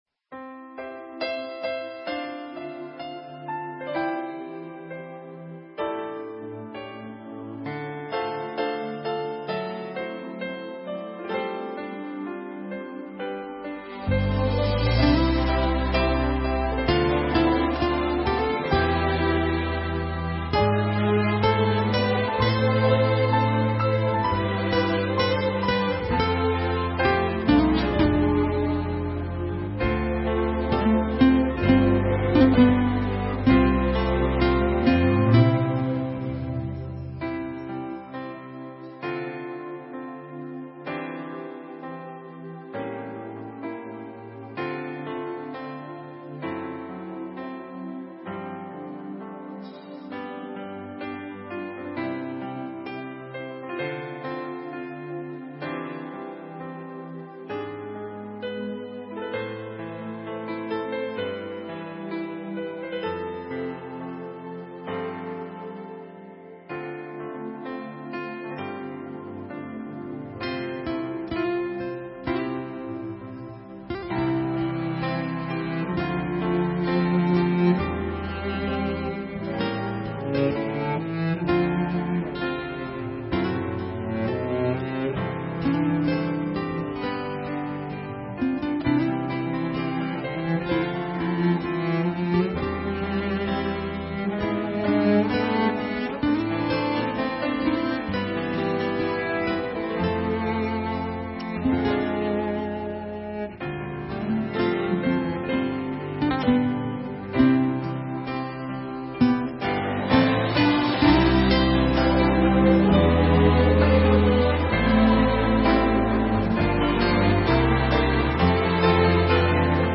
伴奏